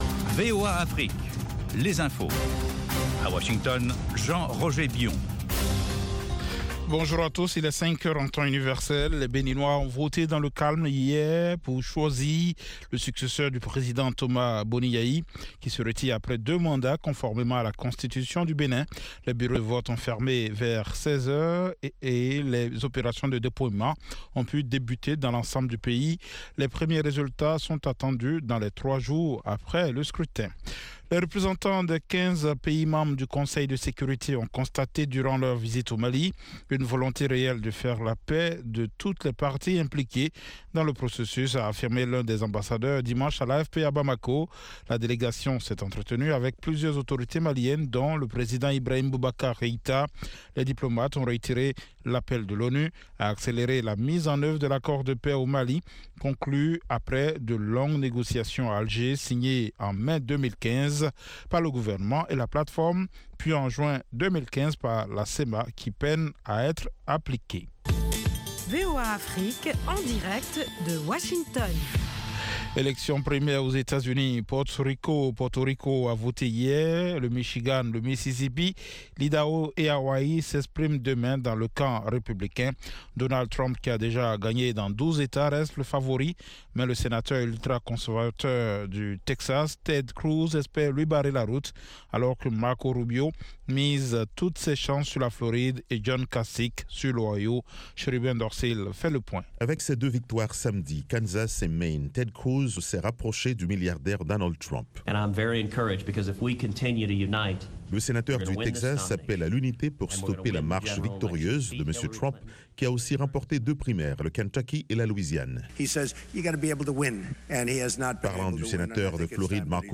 Bulletin
5 min News French